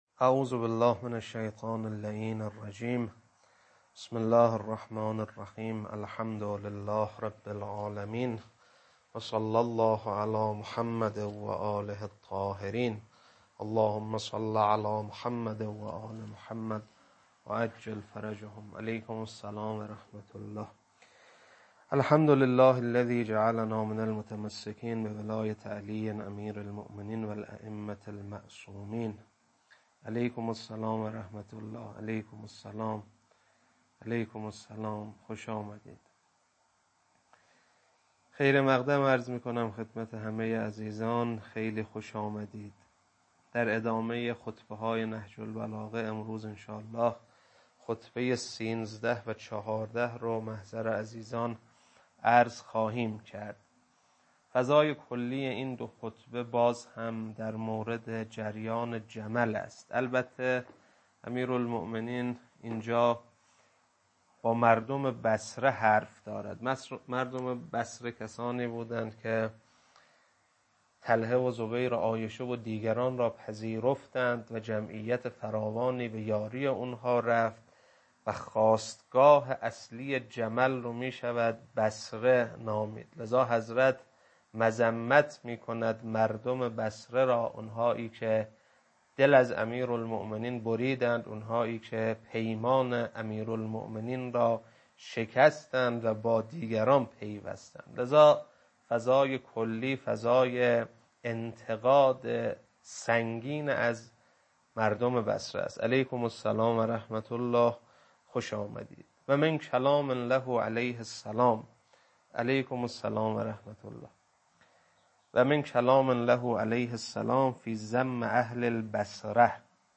خطبه 13 و 14.mp3